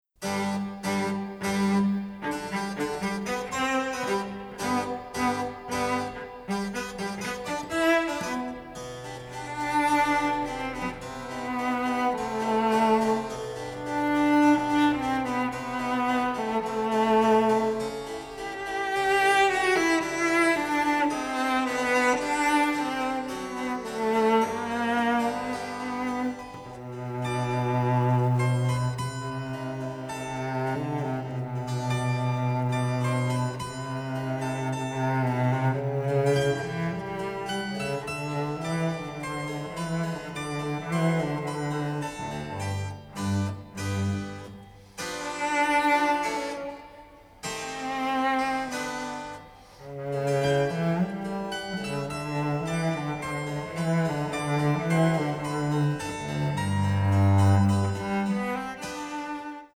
psychedelic cult classics
largely characterized by a baroque feel
children’s vocals, lullabies and a cembalo